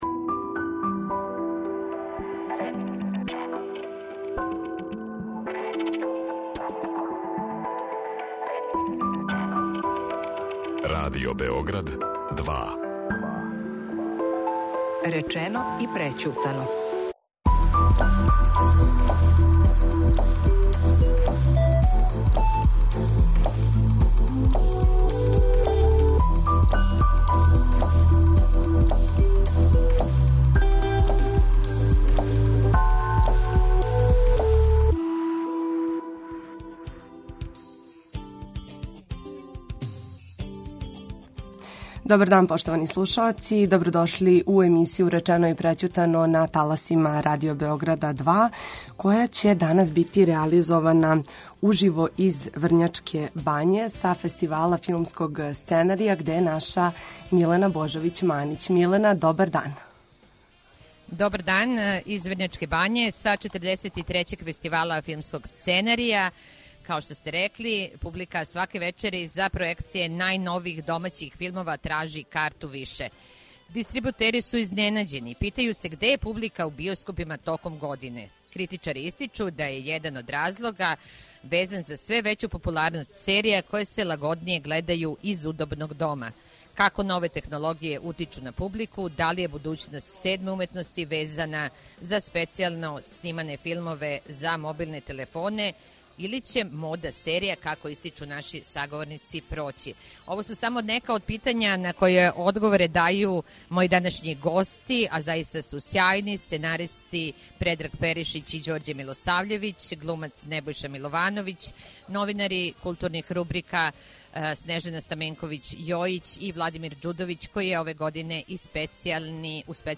Уживо са 43. Фестивала филмског сценарија у Врњачкој бањи.